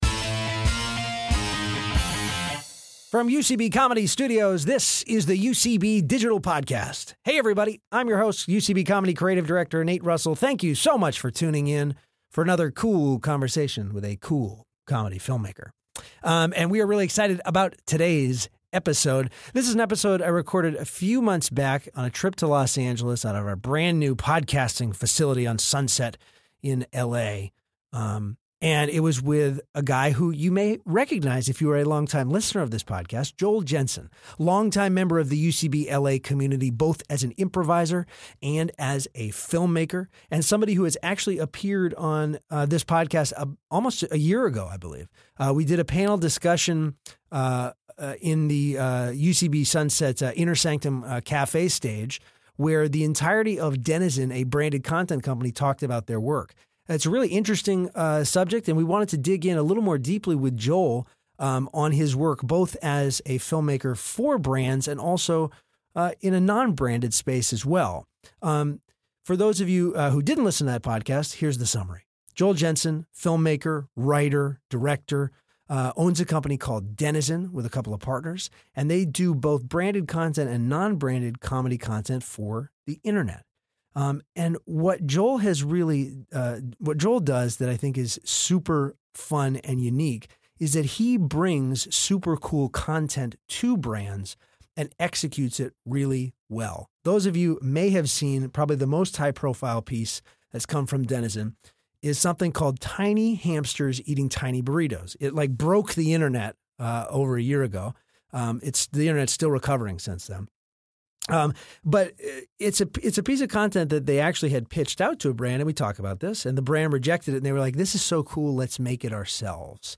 They discuss what it means to be a creator, how the lines are blurring between content and advertisement, and how improv and comedy can impact your professional life. Recorded at UCB Comedy Studios West in Los Angeles.